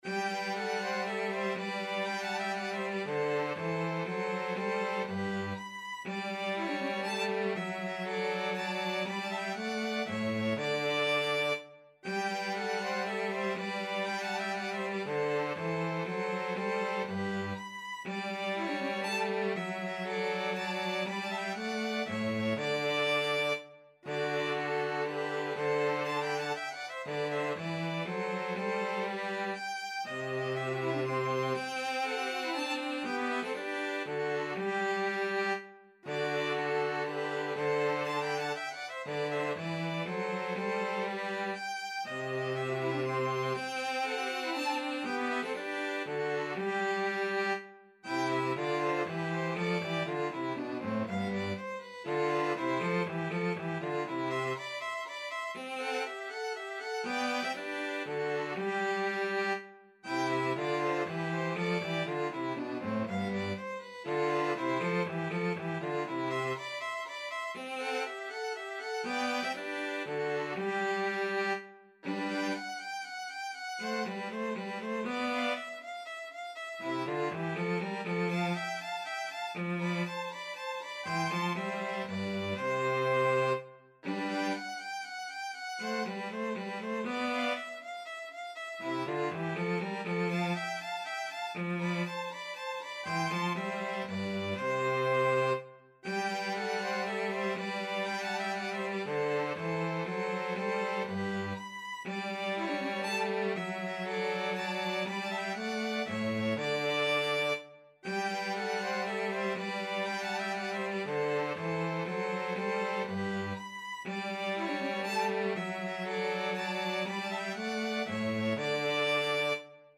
G major (Sounding Pitch) (View more G major Music for 2-Violins-Cello )
3/4 (View more 3/4 Music)
2-Violins-Cello  (View more Intermediate 2-Violins-Cello Music)
Classical (View more Classical 2-Violins-Cello Music)
7-minuets-k-61b_1_2VNVC.mp3